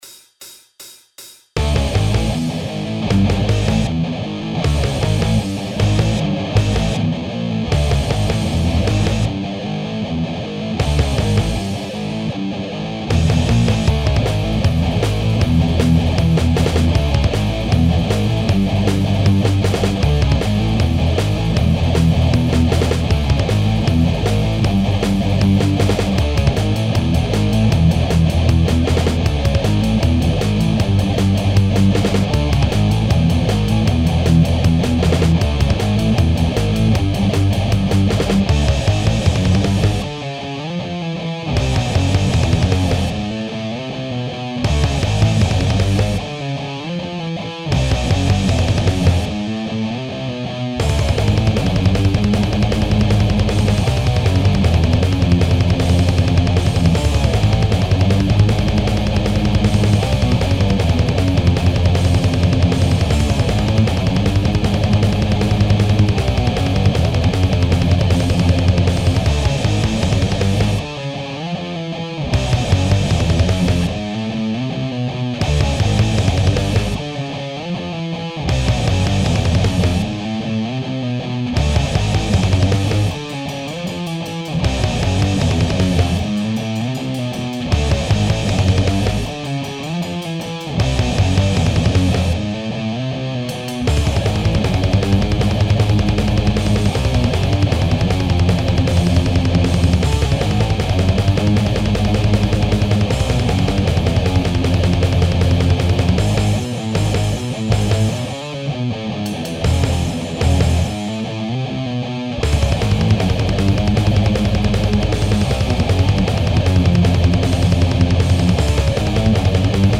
It is definitely a straight ahead thrasher but I tried to change the song structure a little and extend and contract it in different ways.